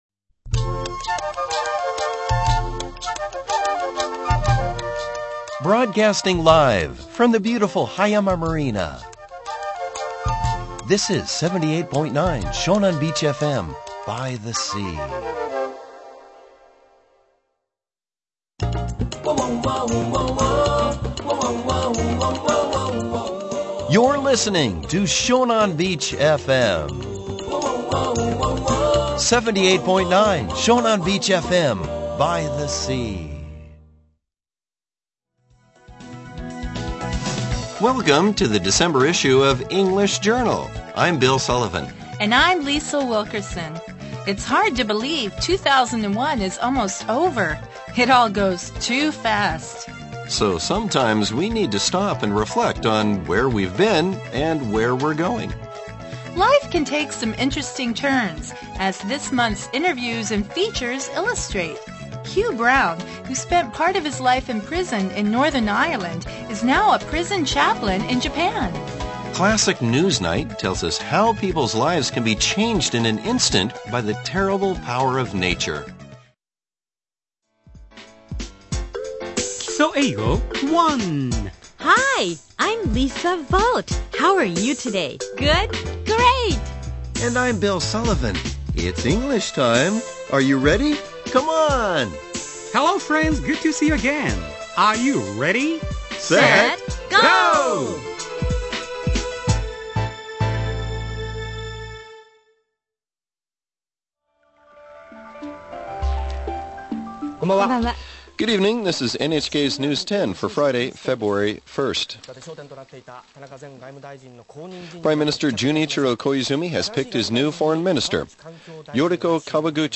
音声サンプル